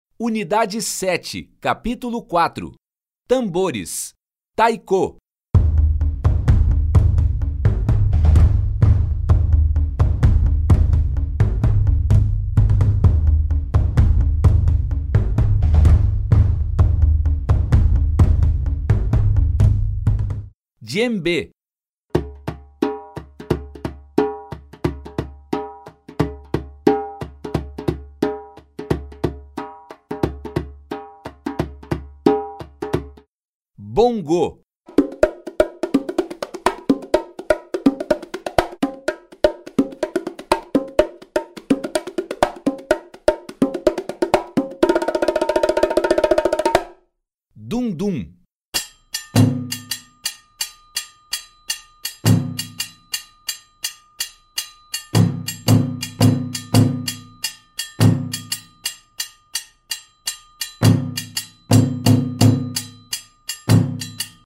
Tambores
p_PRart_un07au_tambores.mp3